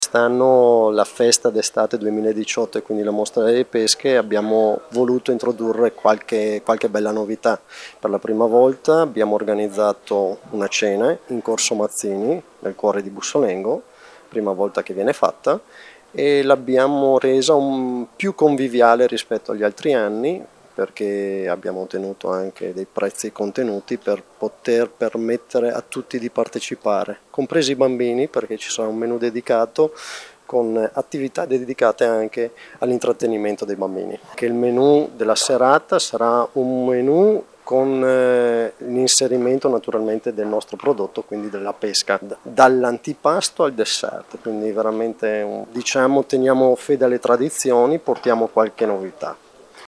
La pesca sarà l’ingrediente base della cena, dall’antipasto al dessert, come confermato dall’ Assessore alle manifestazioni, Massimo Girelli:
Massimo-Girelli-assessore-alle-Manifestazioni-comune-di-Bussolengo-festa-della-pesca.mp3